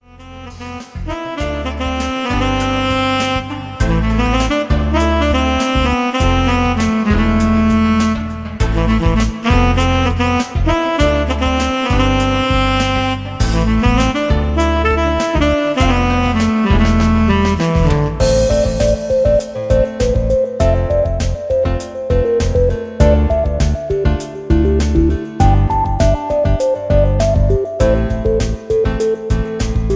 Instrumental Electronic Composition
Tags: sax